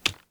keyPress_b.ogg